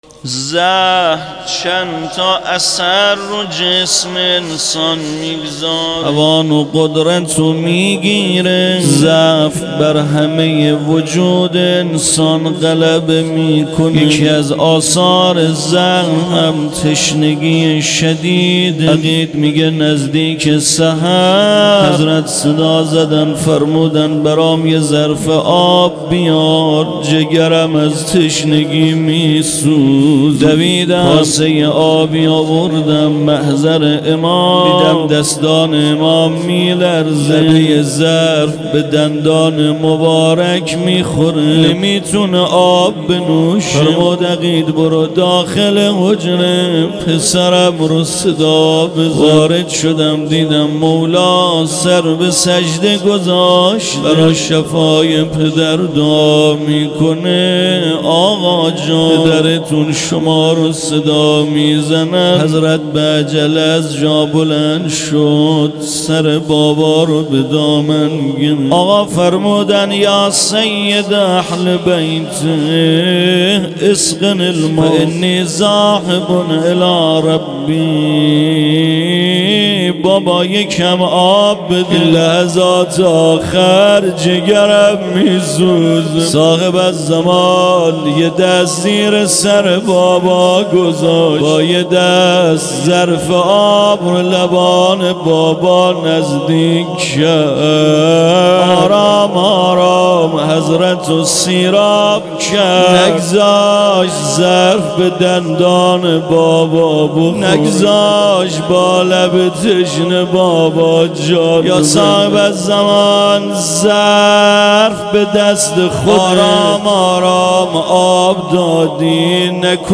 روضه امام حسن عسگری
شهادت امام حسن عسگری